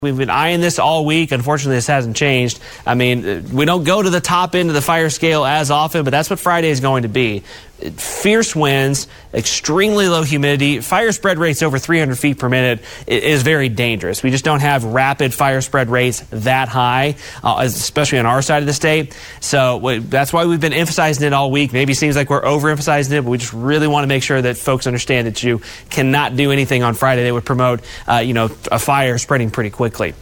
Meteorologist